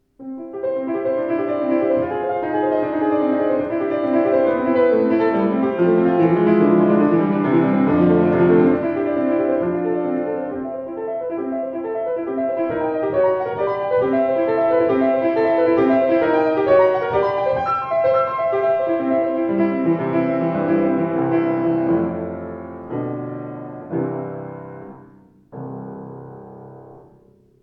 Klangproben eines anderen, baugleichen Steinway Z: